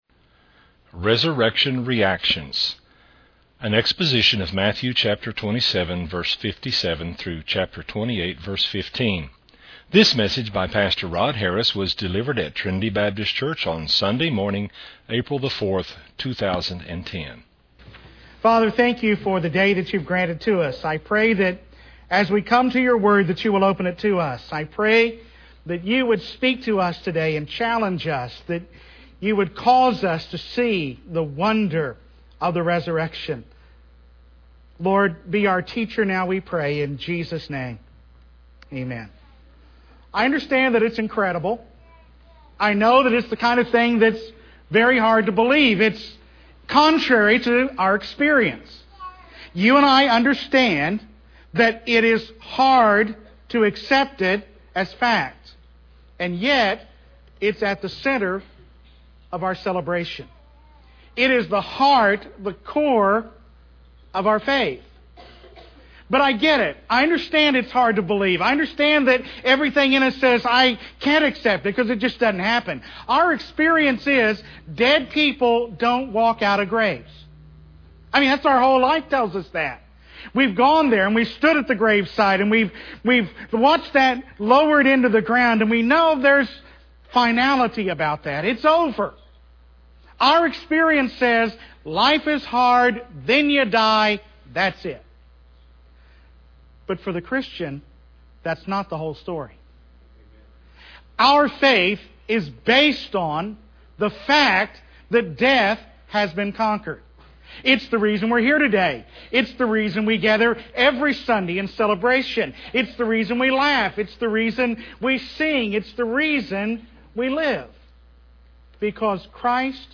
delivered at Trinity Baptist Church on Resurrection Sunday morning